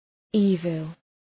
Προφορά
{‘i:vəl}